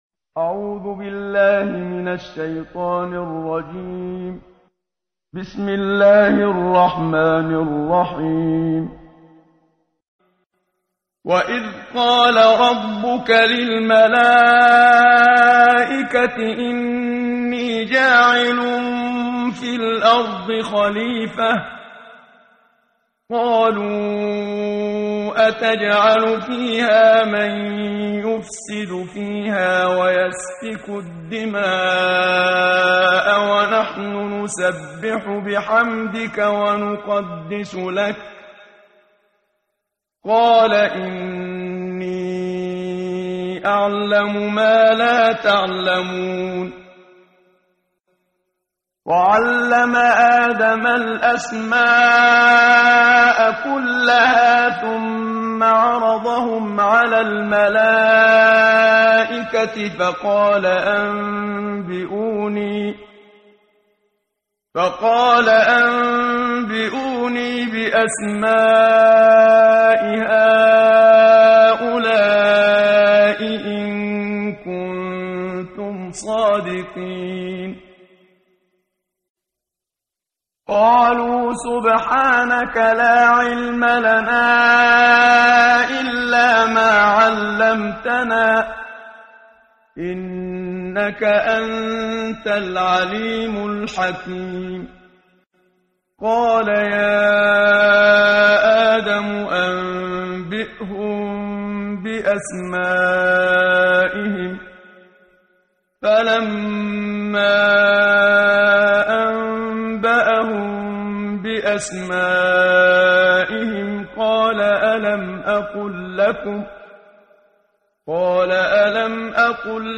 قرائت قرآن کریم ،امروز، صفحه ششم، سوره مبارکه بقره آیات سیم تا سی و هفتم با صدای استاد صدیق منشاوی.